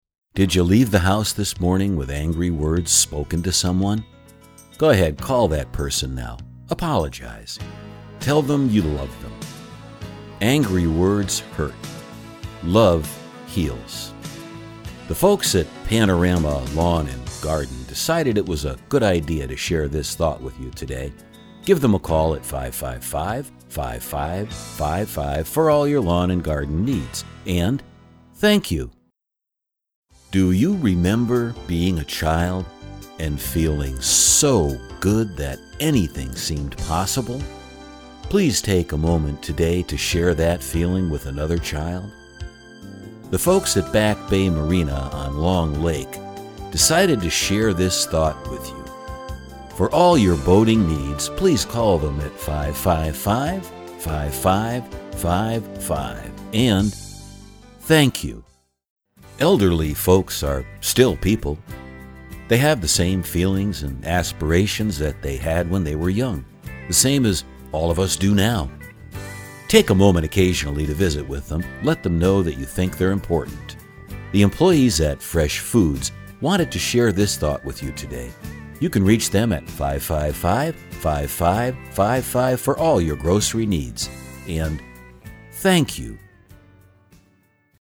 Sprechprobe: Sonstiges (Muttersprache):
Confident, absolutely believable